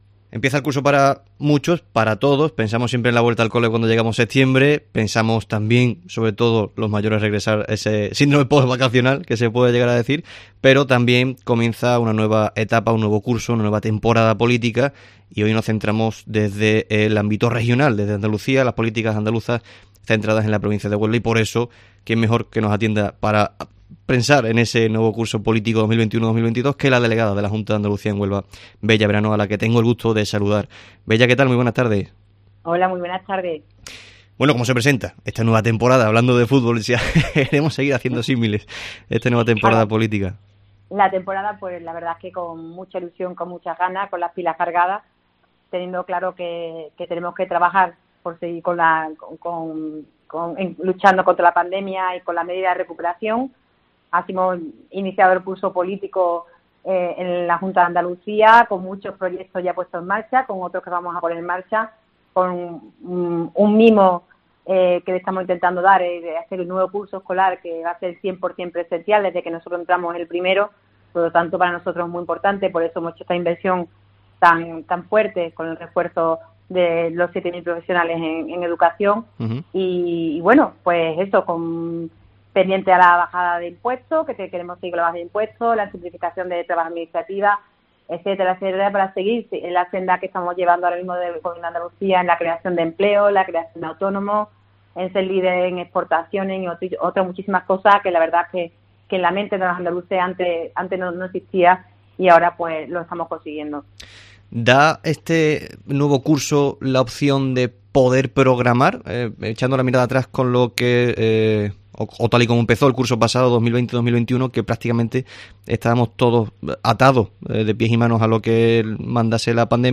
Con el inicio del curso político, COPE Huelva ha charlado con Bella Verano, delegada de la Junta de Andalucía en Huelva, sobre la actualidad y la...